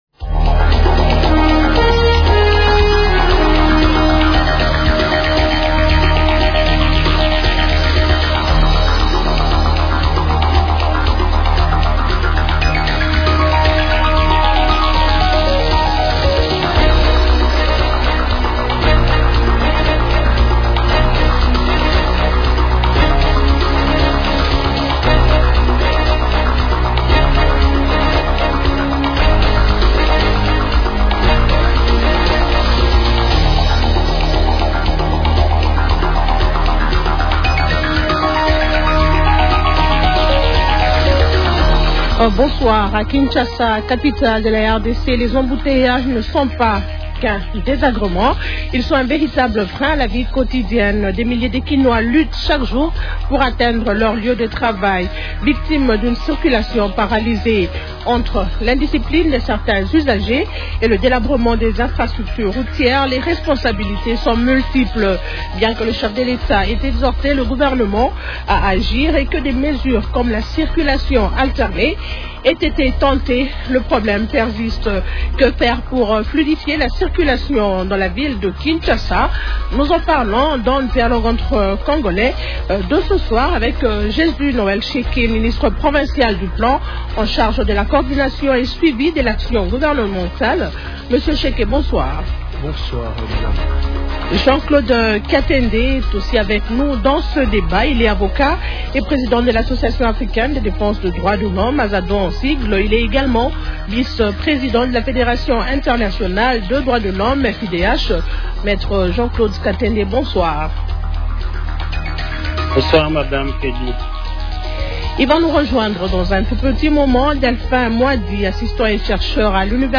Invités : -Jésus-Noel Sheke, ministre provincial du Plan en charge de la coordination et de suivi de l’action gouvernementale.